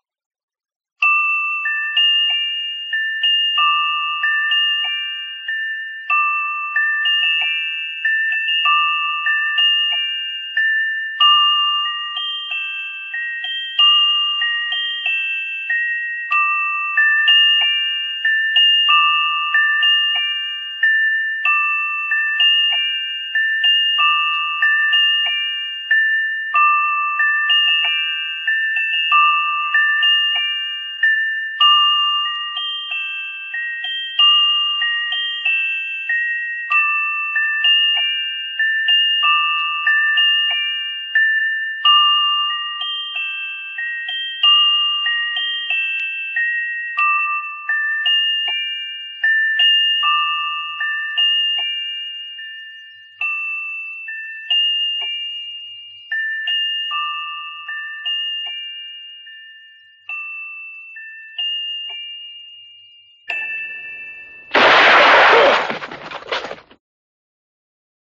When the chimes end.mp3